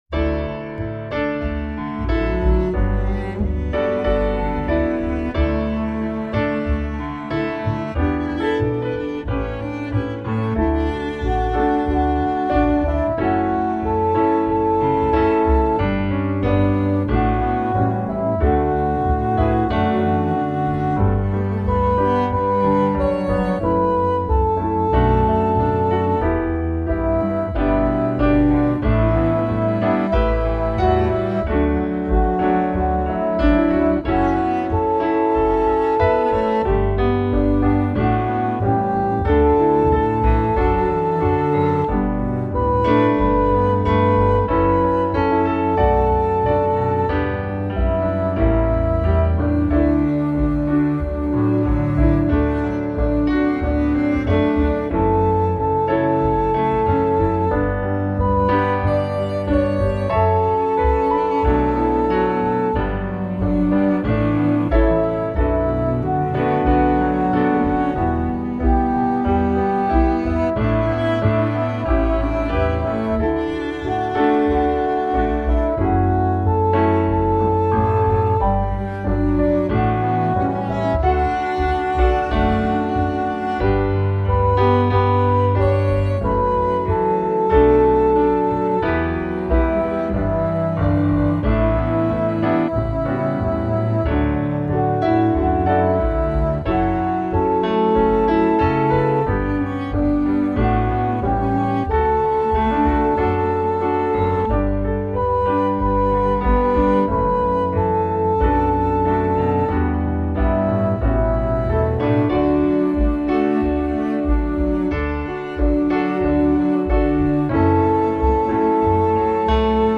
stately song
pretty good backing piano improvising